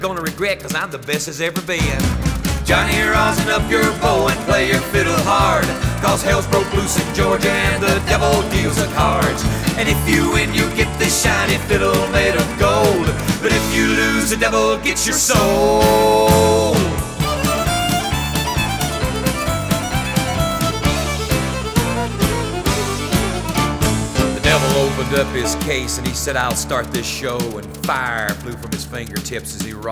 • Country
The song is written in the key of D minor.